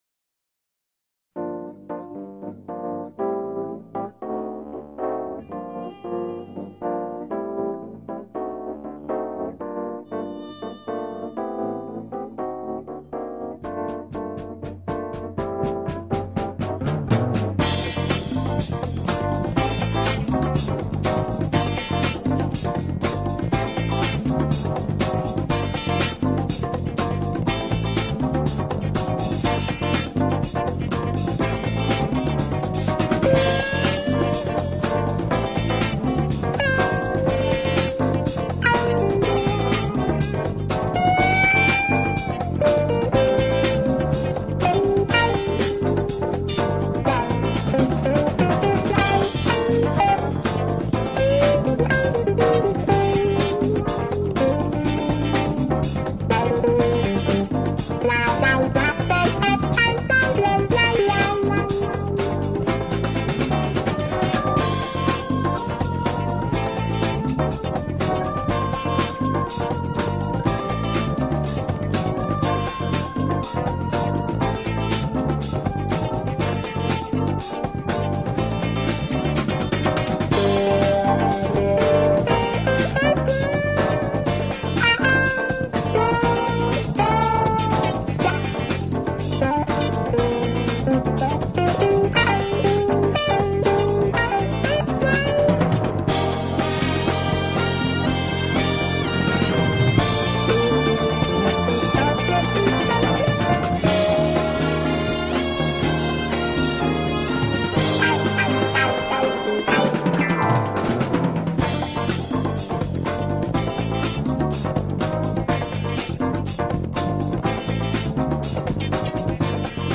Música del Brasil